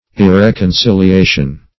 Search Result for " irreconciliation" : The Collaborative International Dictionary of English v.0.48: Irreconciliation \Ir*rec`on*cil`i*a"tion\, n. Lack of reconciliation; disagreement.